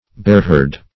Bearherd \Bear"herd`\, n.
bearherd.mp3